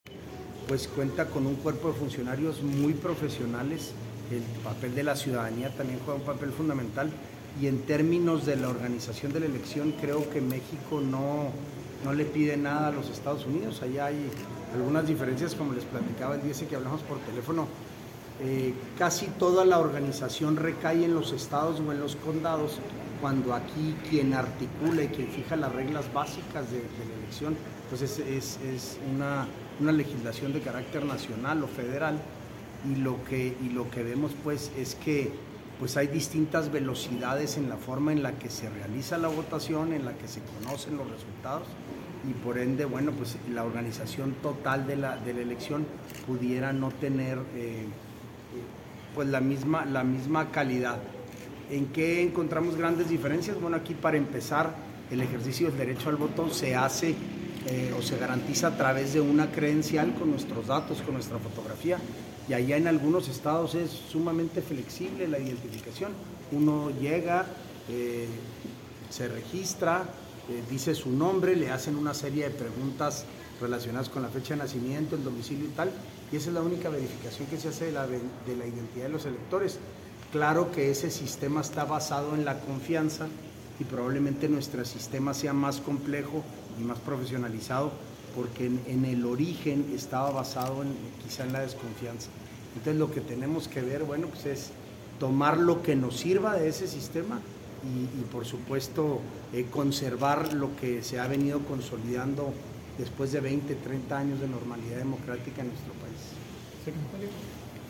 AUDIO: SANTIAGO DE LA PEÑA, SECRETARIO GENERAL DE GOBIERNO (SGG)